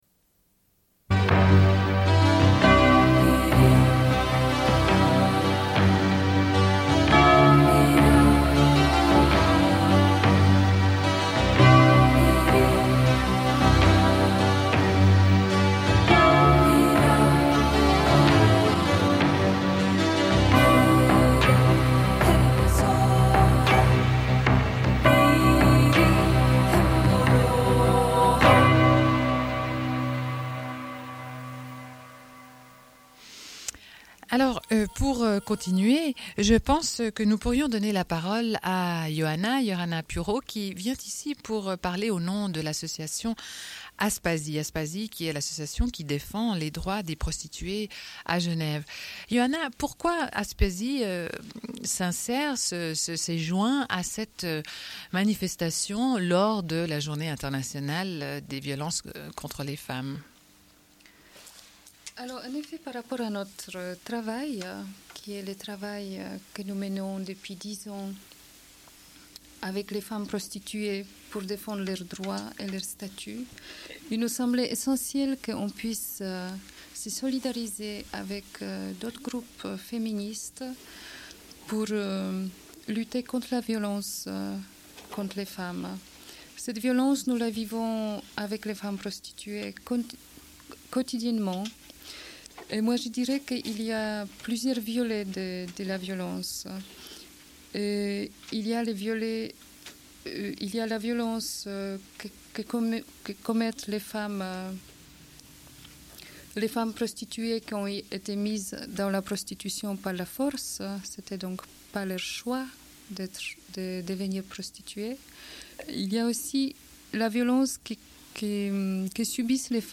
Une cassette audio, face B28:50